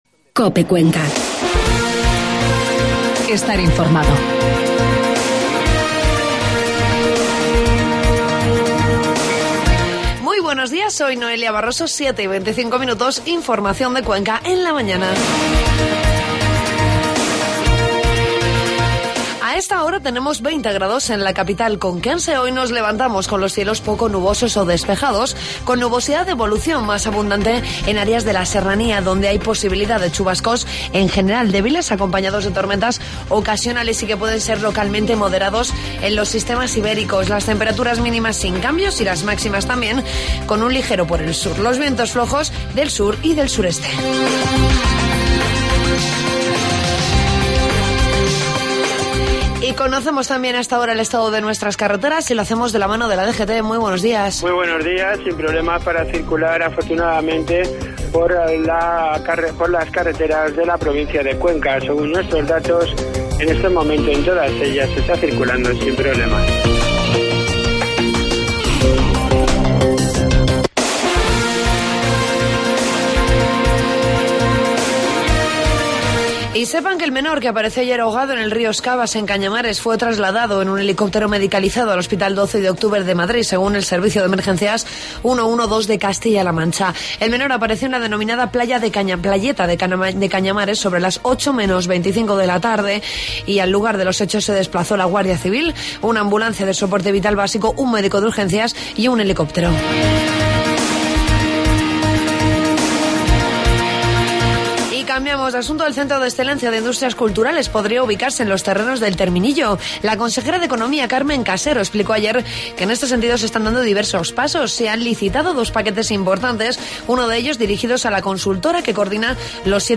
Informativo matinal miércoles 17 de julio